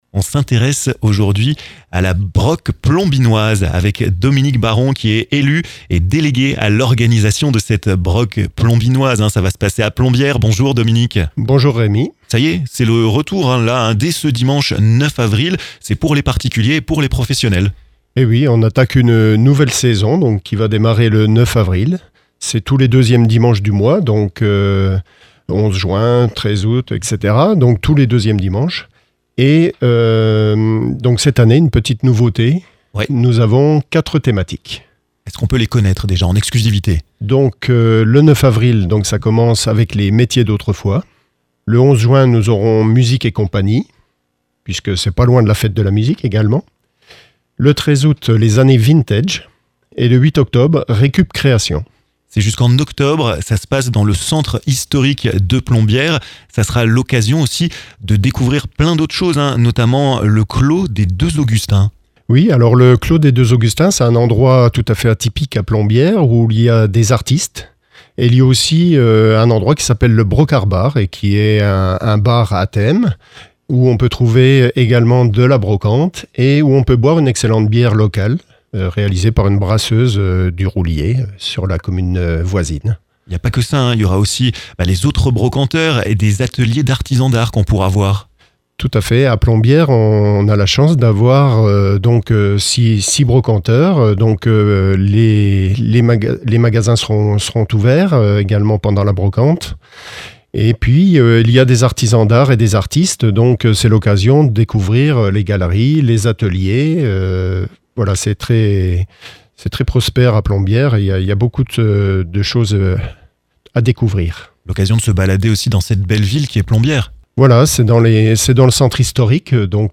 Dominique Baron, élu et délégué à l'organisation, vous présente la Broc'Plombinoise, pour les particuliers et les professionnels, qui se déroulera ce dimanche 9 avril!